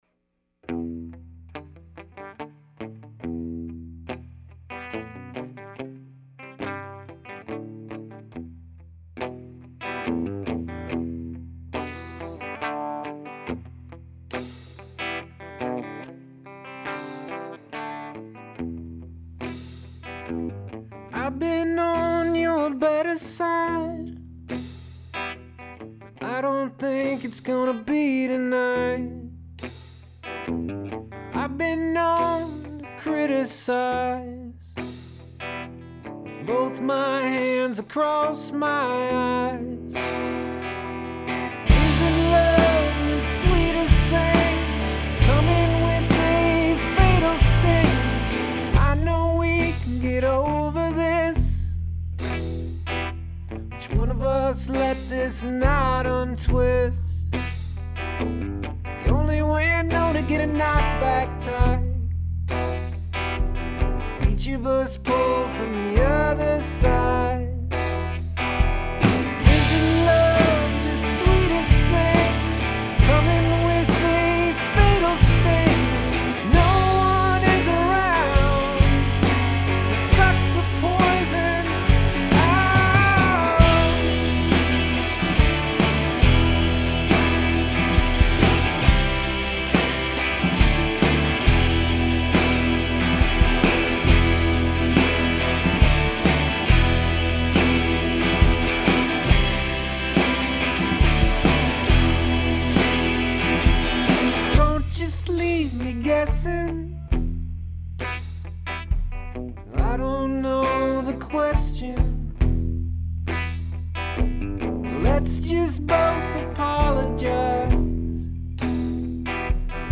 • Indie Rock
• Close and room mics